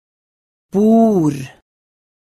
9a Uttale
O-lyden: Lytt, les og gjenta